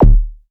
Percs
REDD PERC (38).wav